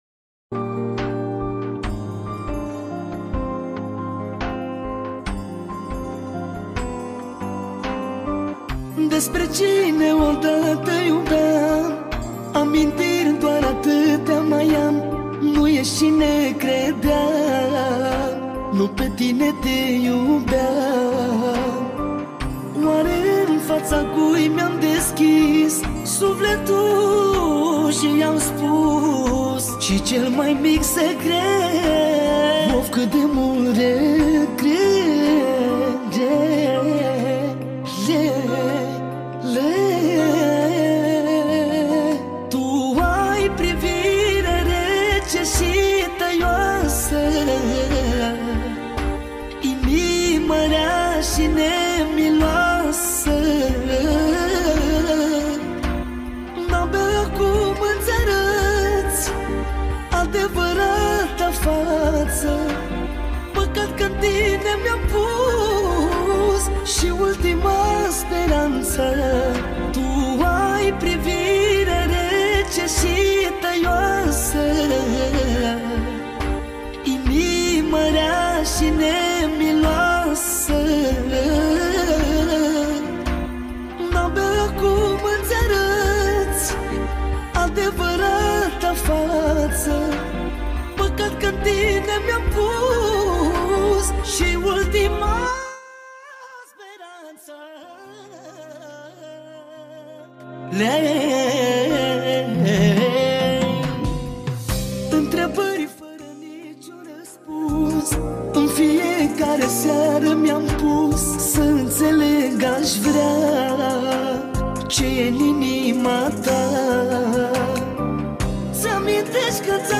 Data: 31.10.2024  Manele New-Live Hits: 0